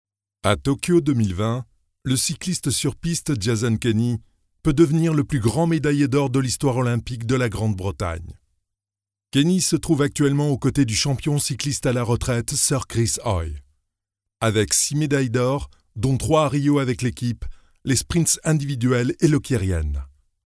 French voice talent